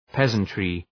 Προφορά
{‘pezəntrı}
peasantry.mp3